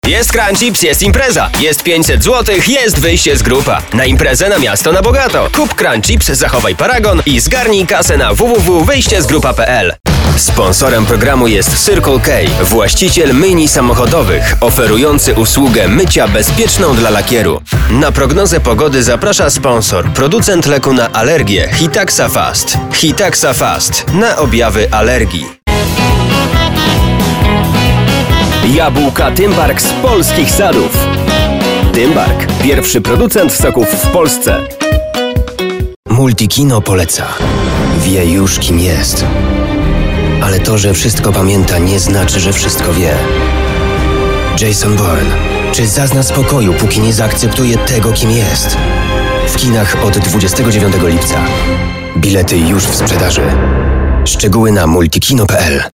Male 20-30 lat
Strong and clear sound in mid-range registers.
Narracja lektorska
Demo lektorskie